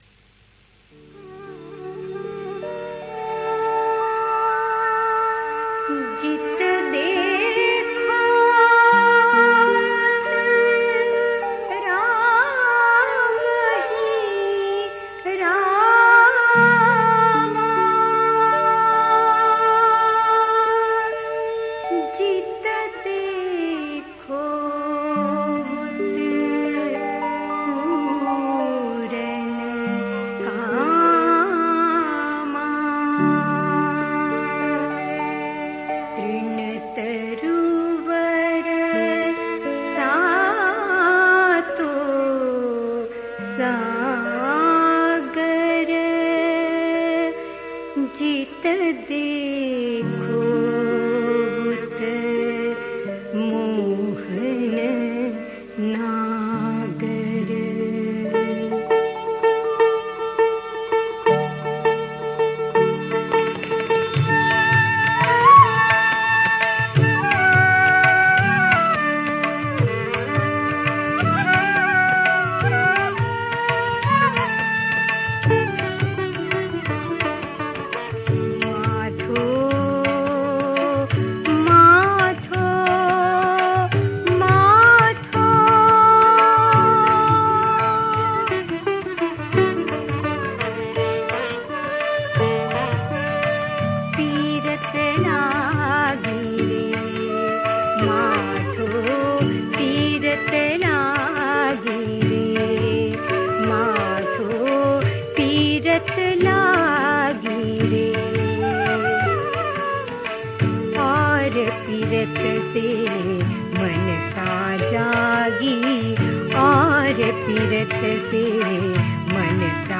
समर्थ साधना भजन 3 # Samartha Sadhana Bhajan 3